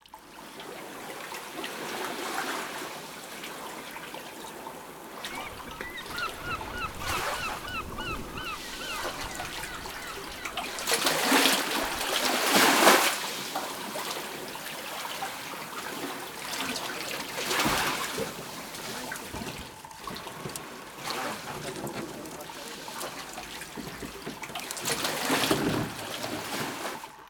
This soundscape is purely descriptive. It features clips of sloshing waves and seabirds, and also includes a clip of people boarding a boat while speaking Spanish and Italian.